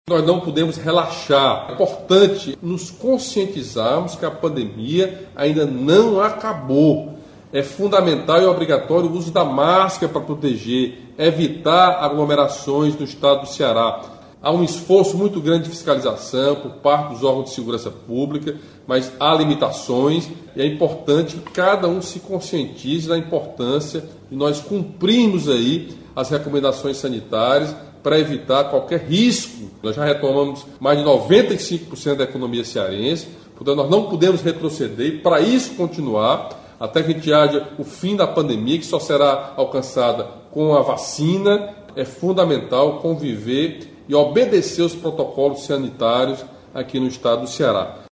Em sua transmissão ao vivo semanal, nesta quinta-feira (10), o governador Camilo Santana reforçou que a população precisa se conscientizar e cumprir as medidas sanitárias estabelecidas.